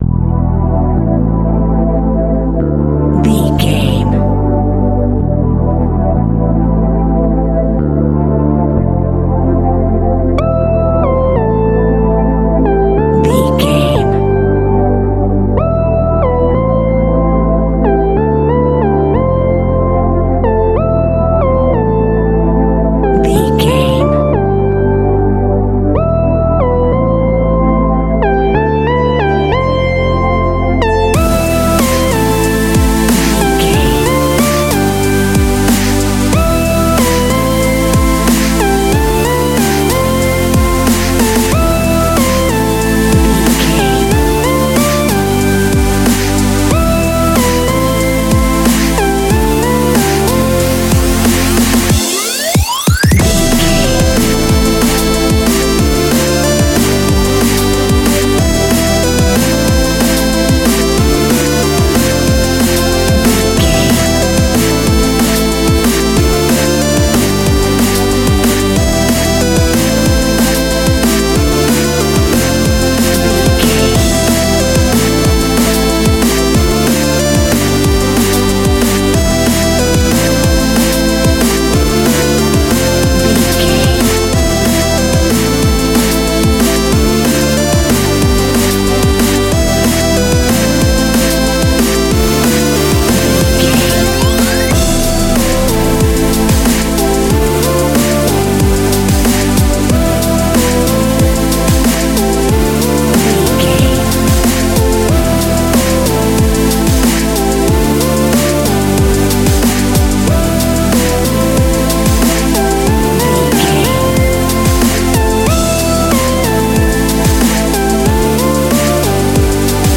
Aeolian/Minor
Fast
aggressive
dark
intense
energetic
driving
synthesiser
drum machine
Drum and bass
Techstep
synth leads
synth bass